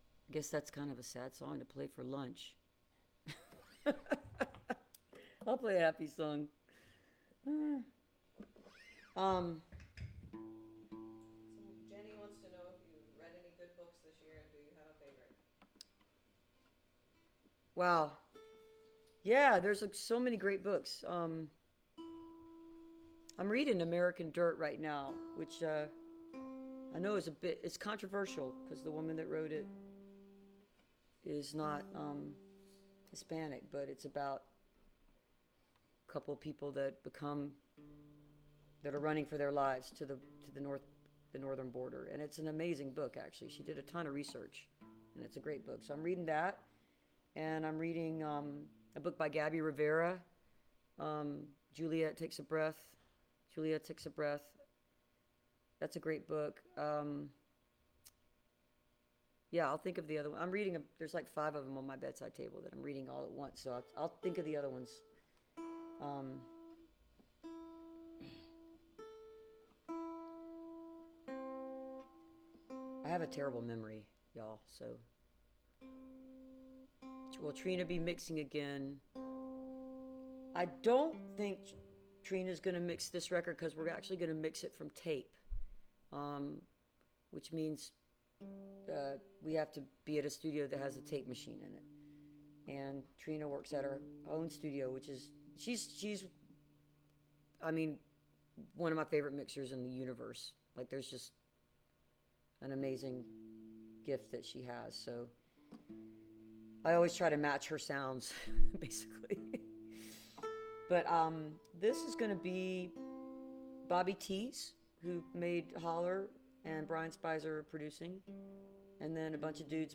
(captured from the facebook live stream)
09. talking with the crowd (2:26)